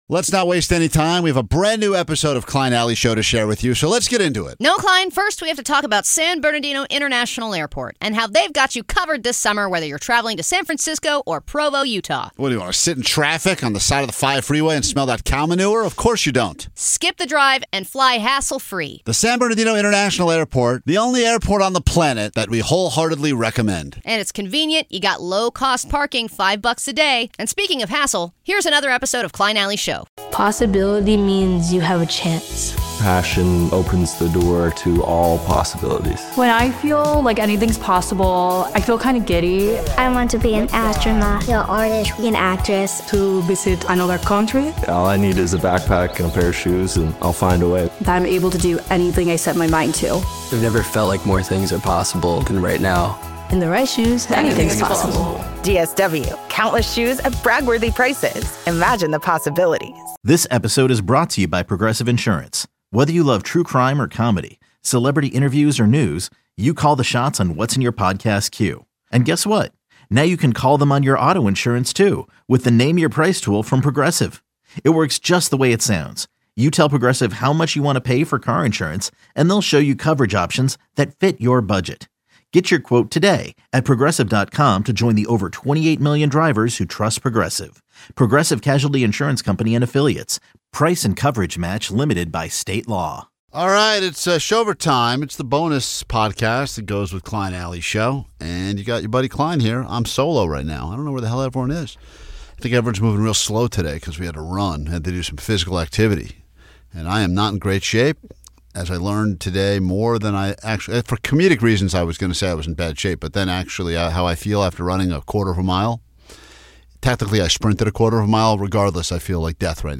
RSS 🧾 Download transcript Summary In today's bonus pod we interview to potential interns!